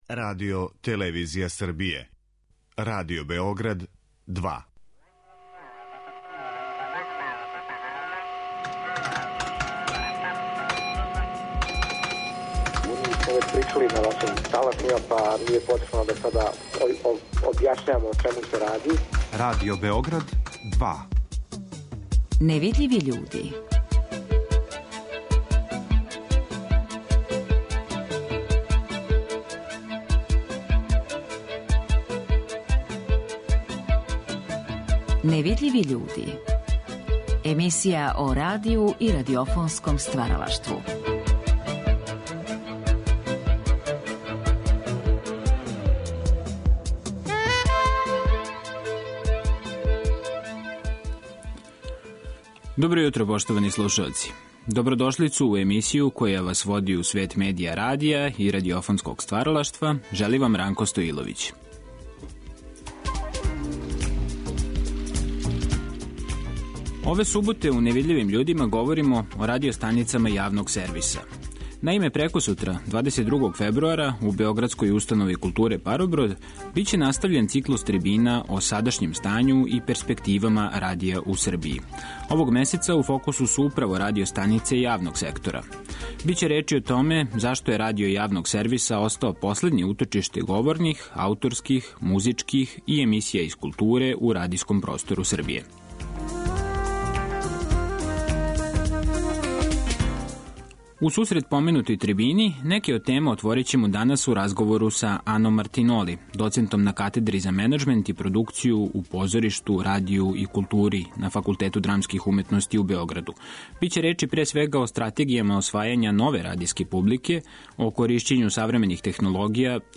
Ови разговори вођени су у оквиру циклуса емисија "Гост Другог програма" 1975. године.